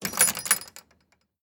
Gate Close.ogg